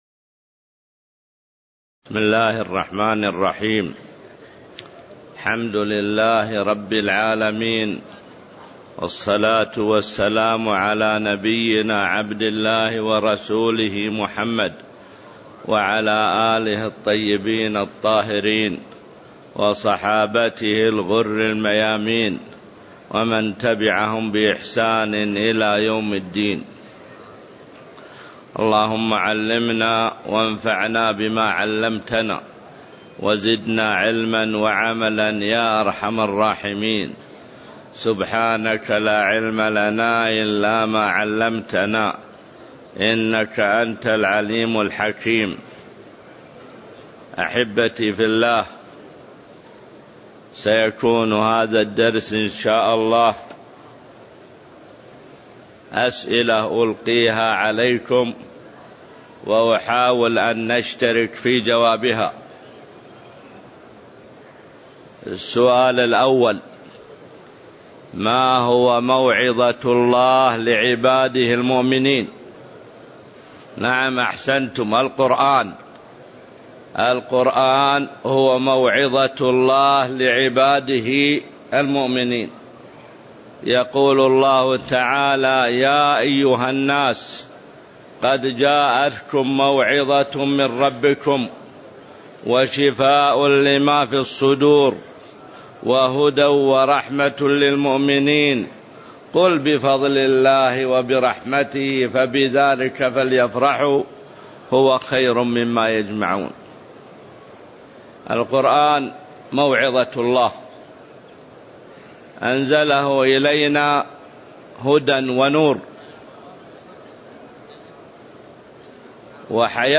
تاريخ النشر ١١ شعبان ١٤٣٩ المكان: المسجد النبوي الشيخ: معالي الشيخ د. عبدالله بن محمد المطلق معالي الشيخ د. عبدالله بن محمد المطلق فضل قراءة القرآن وتدّبر آياته والعمل به